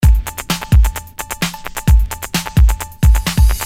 Electro rythm - 130bpm 26